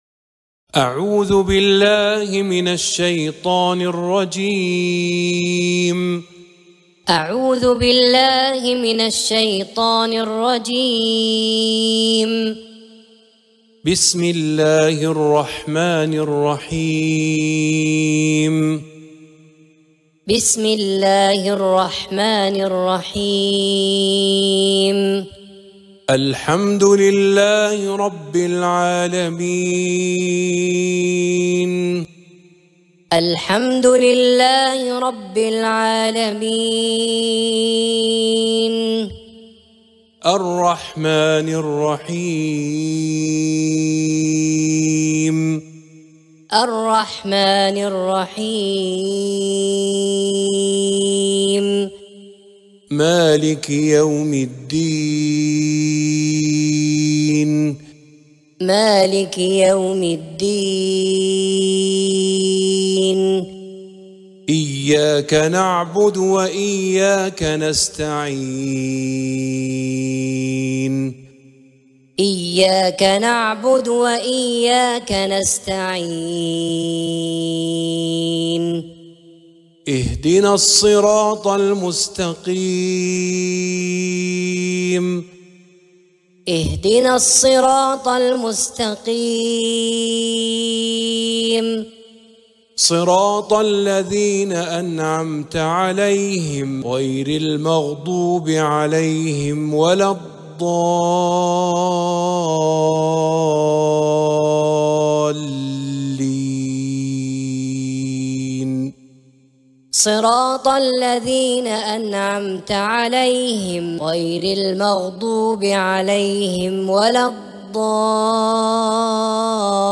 23.-🇺🇸-Lets-learn-Surah-Al-Fatiha-learning-with-repetition-teachers-Mus-haf-1.mp3